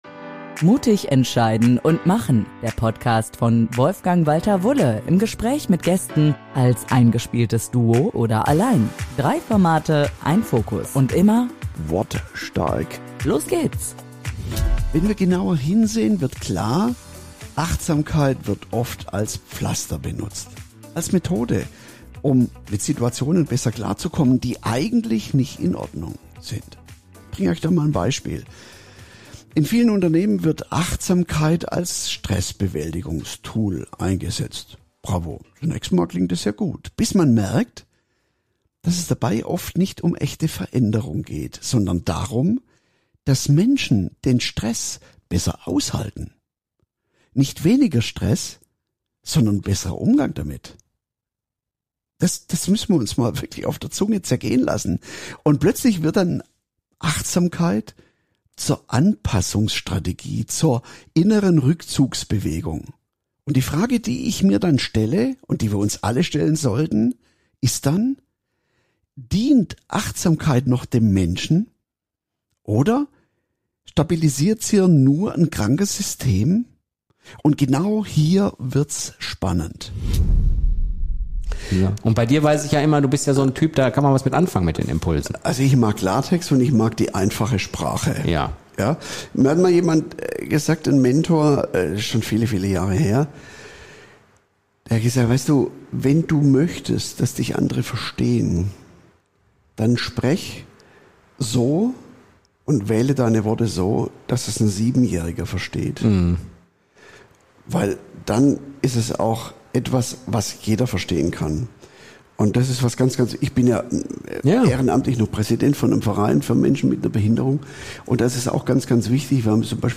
Du hörst Ausschnitte aus Gesprächen über